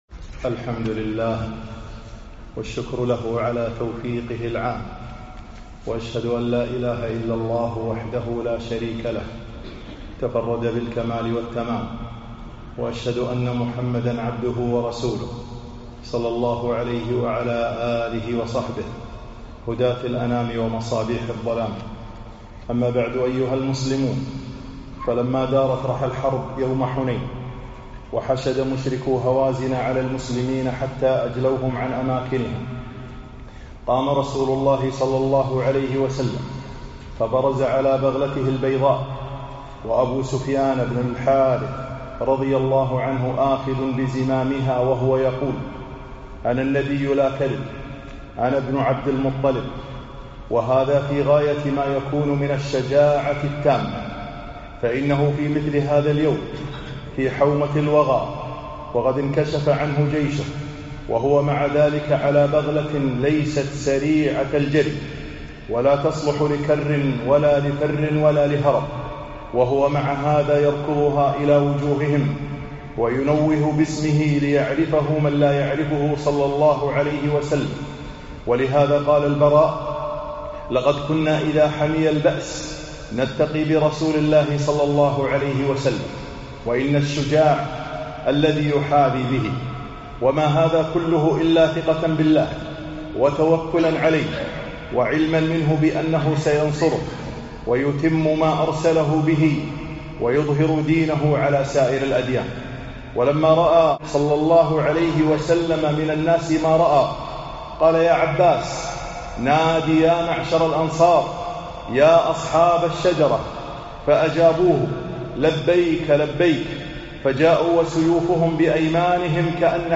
خطب السيرة النبوية 25